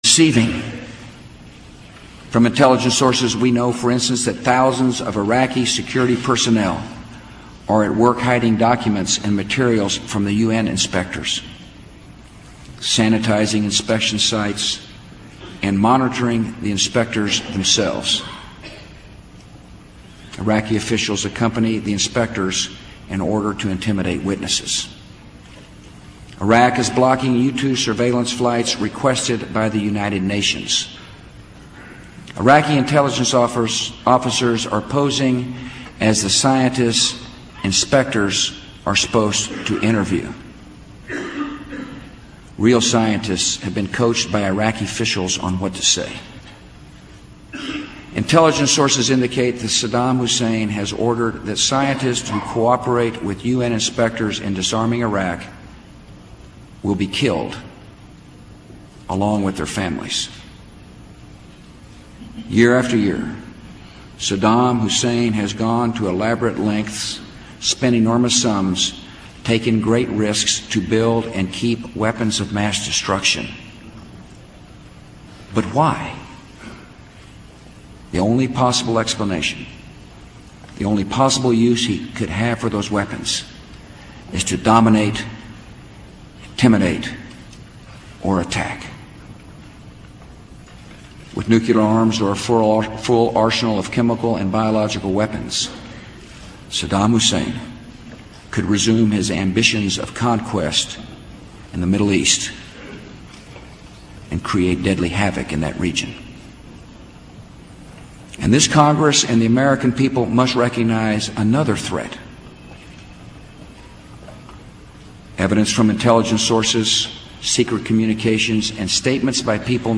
2003 State of the Union Address 9
Tags: George W. Bush State of the Union George W. Bush State of the Union George W. Bush speech President